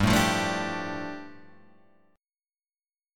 G7sus2 chord {3 0 3 0 3 3} chord